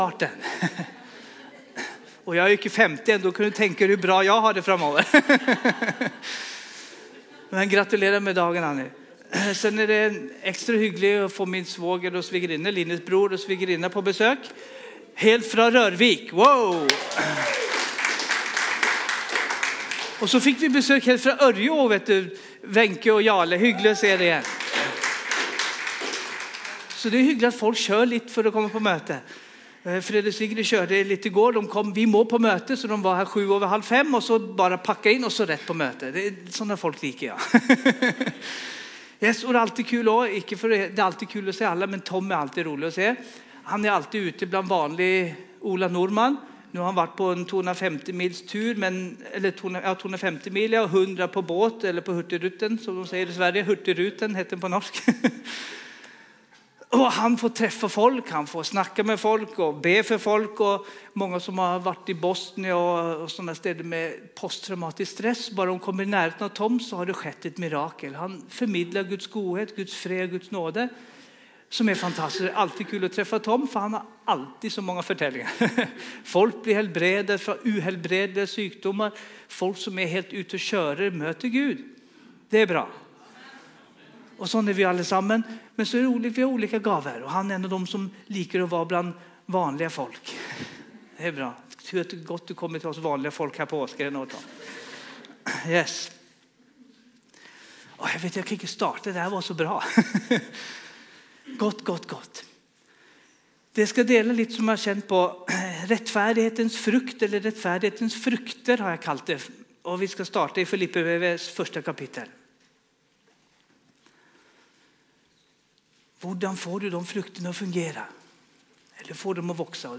Gudstjenester